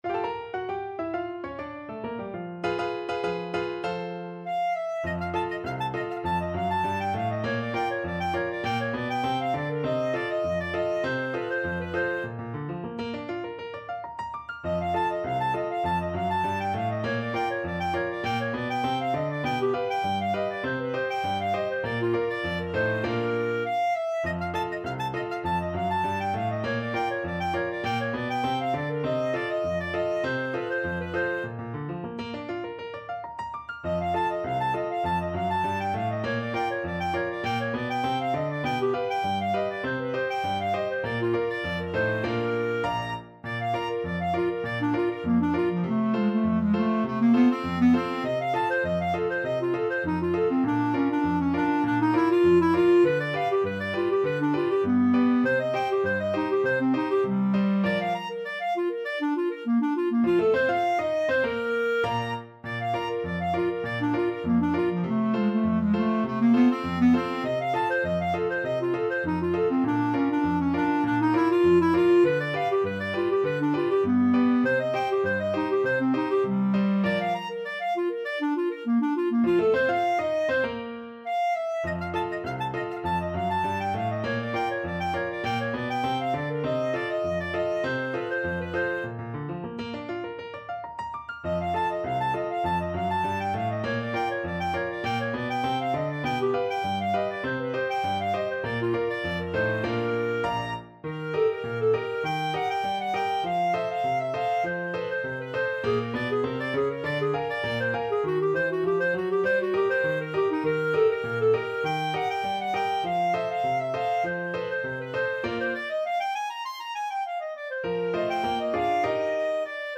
Clarinet
Bb major (Sounding Pitch) C major (Clarinet in Bb) (View more Bb major Music for Clarinet )
Moderato
2/4 (View more 2/4 Music)